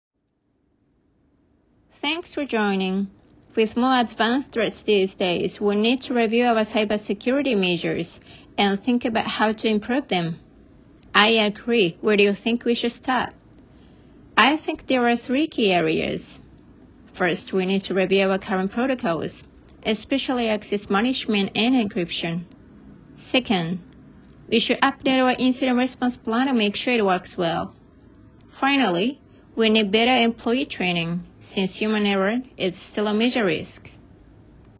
各レベルのモデル音声を掲載しますので、レベル選びや提出時の参考になさってください。
どれも音声変化や発音、リズムを意識した音声となっております。
A, Bと人によって声色を変えてくださる方もいらっしゃいます。